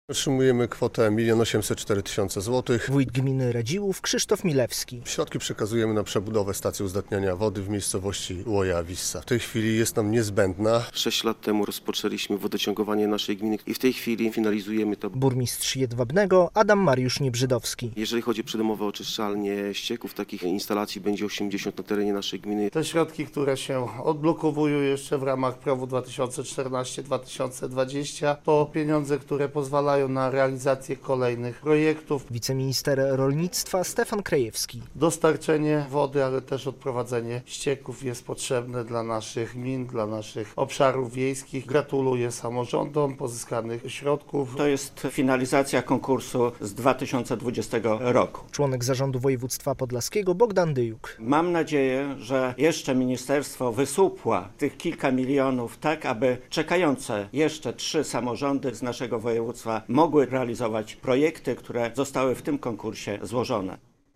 relacja
W poniedziałek (24.02) w Urzędzie Marszałkowskim Województwa Podlaskiego włodarze samorządów podpisali umowy w tej sprawie.
Dostarczenie wody, ale też odprowadzenie ścieków jest niezwykle potrzebne dla naszych gmin, dla naszych obszarów wiejskich. Gratuluję samorządom pozyskanych środków - mówił podczas podpisywania umów wiceminister rolnictwa Stefan Krajewski.